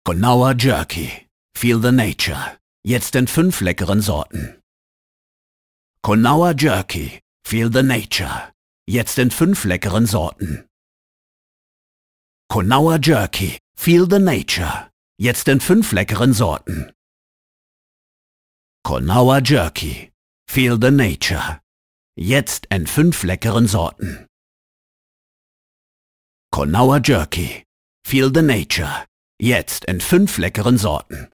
Professioneller deutscher Sprecher / voice-over.
Sprechprobe: Werbung (Muttersprache):
professional voice over, german speaking narrator (voice over, dubbing actor, video games, audio book, radio drama, docoumentary, advertising, poetry etc.).